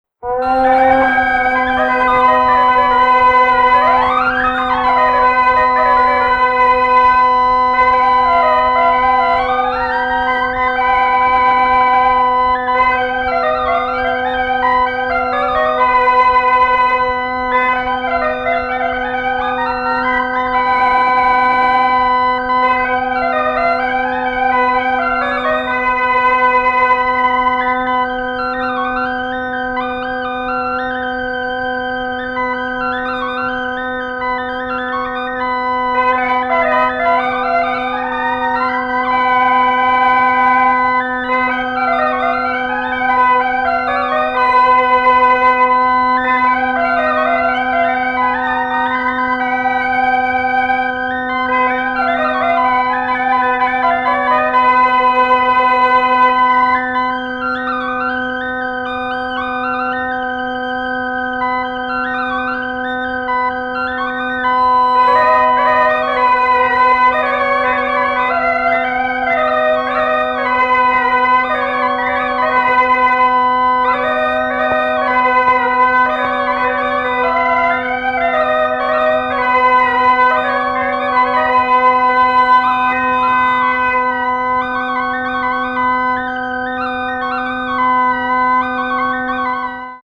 最高！フランスのケルト文化圏“ブルターニュ”における忘れられた伝統音楽を掘り起こす極上アンソロジー！
神々しく荘厳なオルガン演奏や合唱を楽しめます！